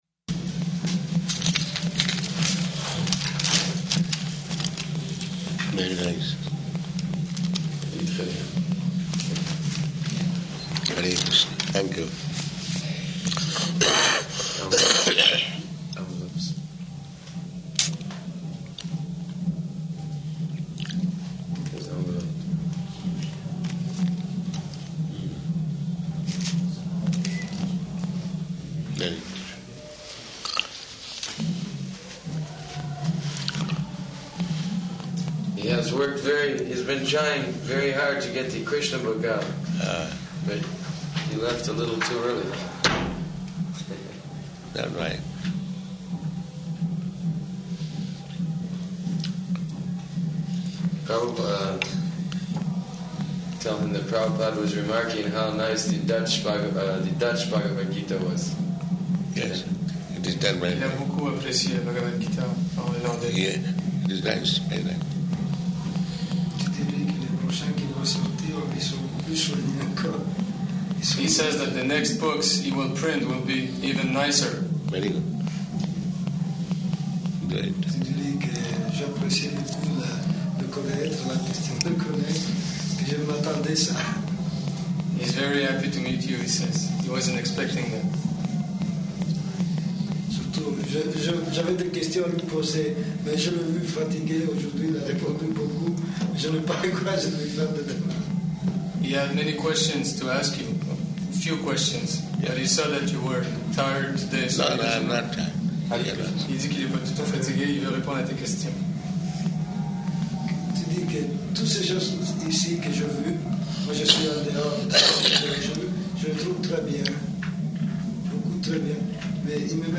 Type: Conversation
Location: New Māyāpur